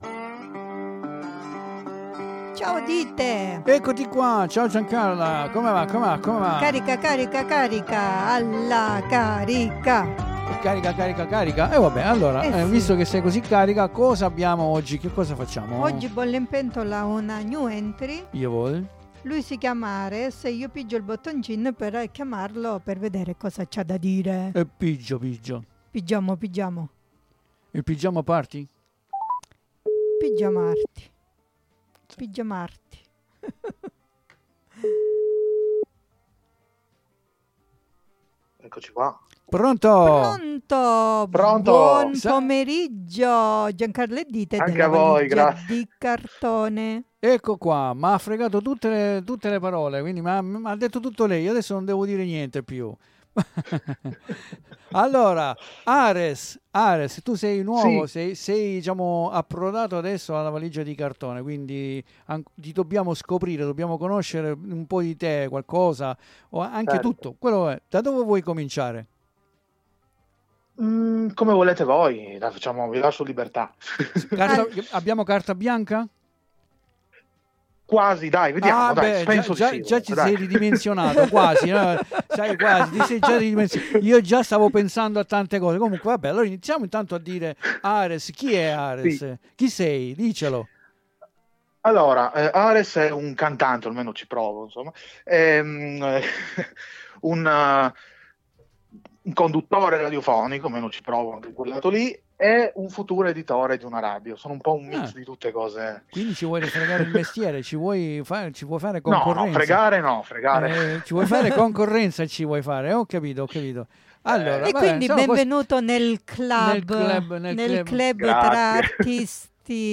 IN DESCRIZIONE LA SUA INTERVISTA .